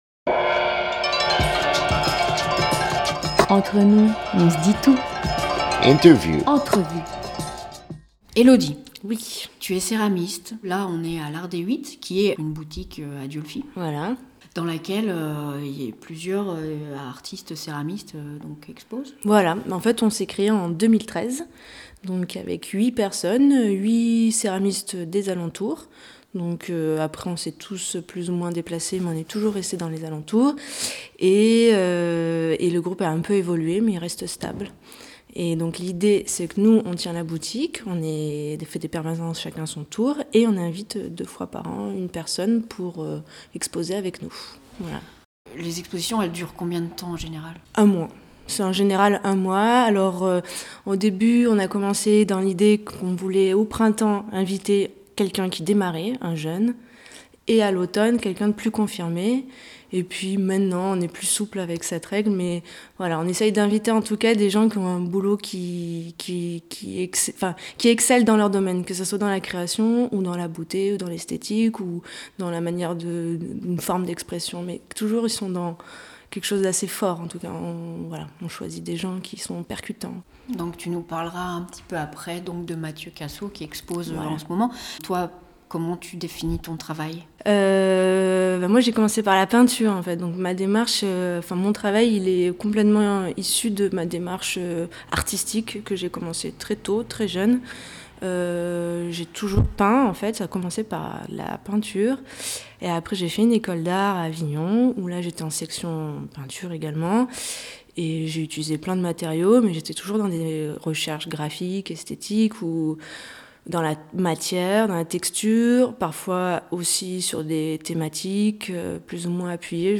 1 septembre 2017 15:03 | Interview